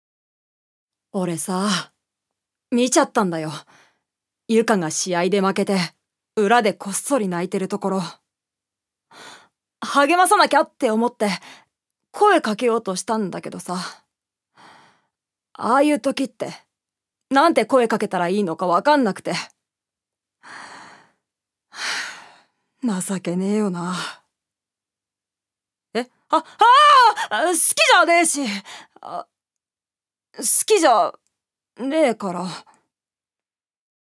預かり：女性
セリフ４